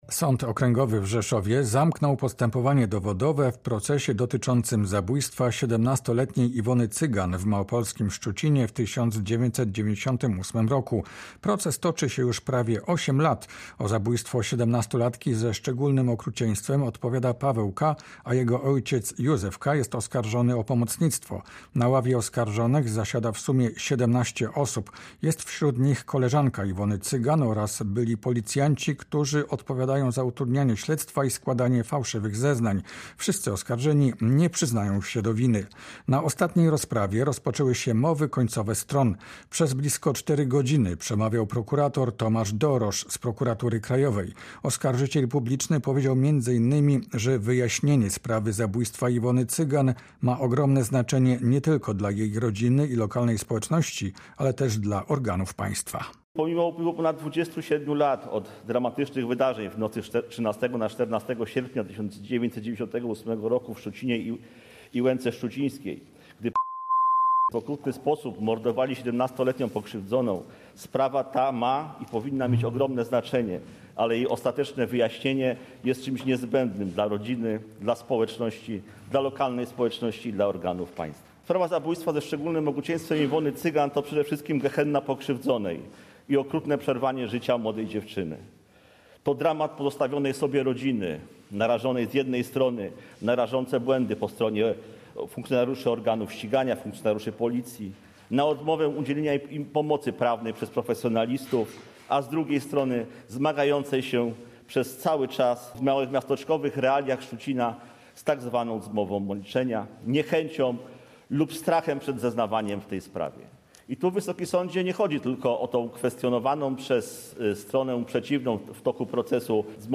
audycja